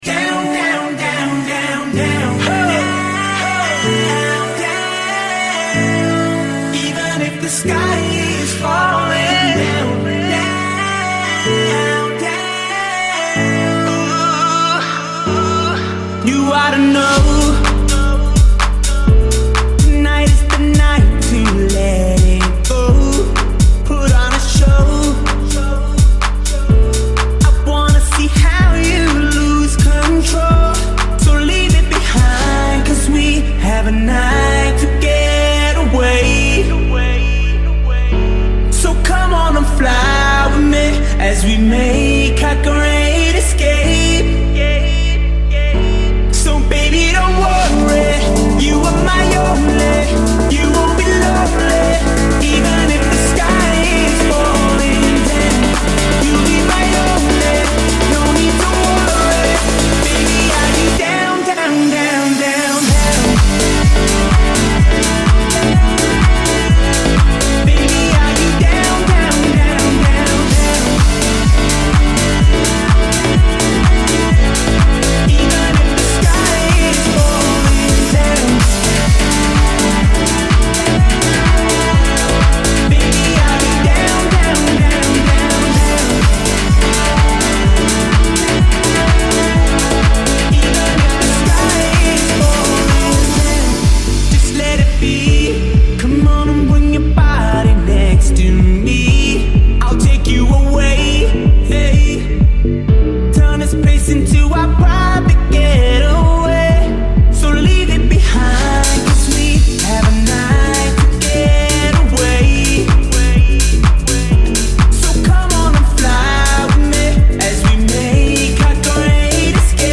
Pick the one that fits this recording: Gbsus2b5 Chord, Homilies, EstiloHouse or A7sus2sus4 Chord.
EstiloHouse